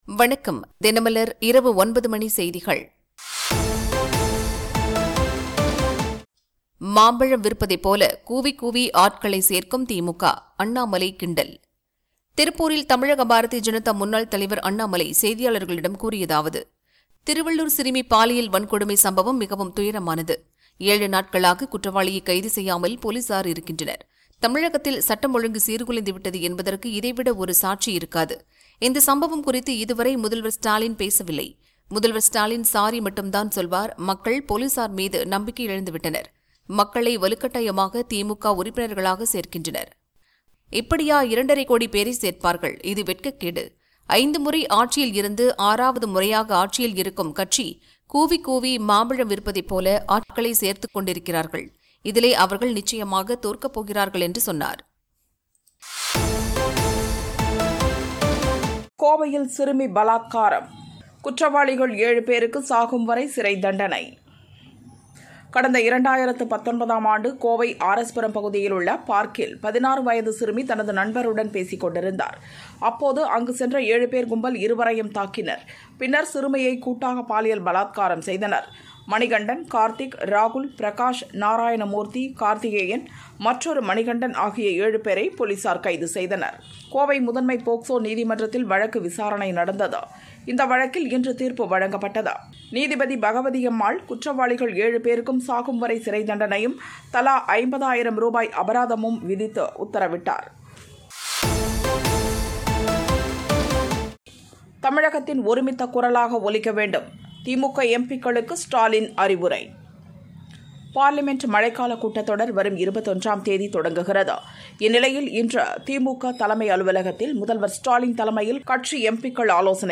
தினமலர் இரவு 9 மணி செய்திகள் - 18 July 2025